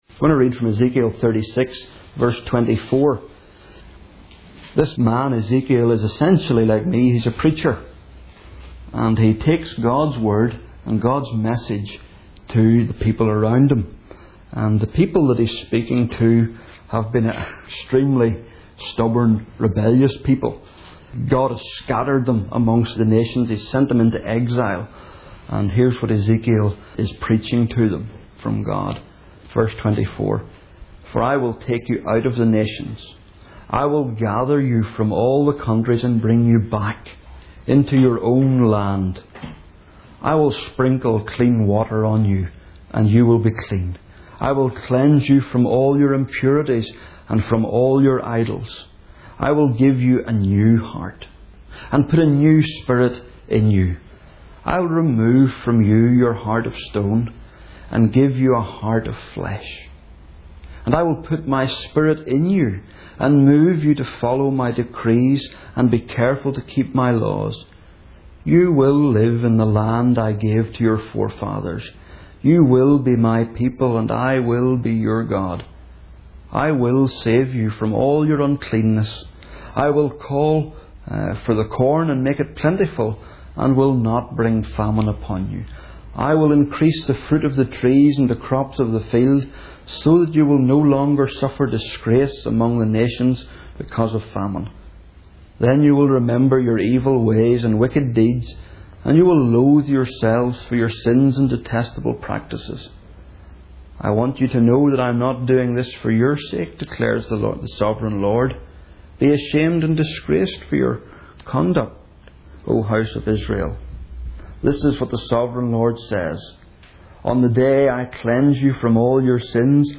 Single Sermons